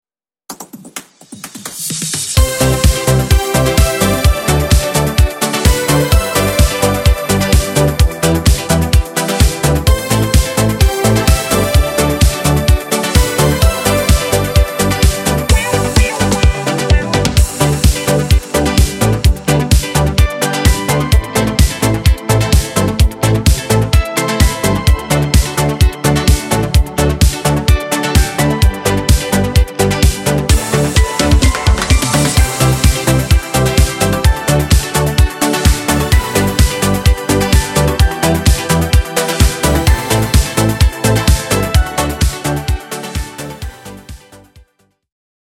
aranżacja w nowej odświeżonej wersji
Disco Polo